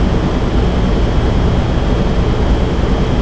enginermAMB.wav